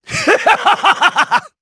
Lusikiel-Vox_Attack4_jp_b.wav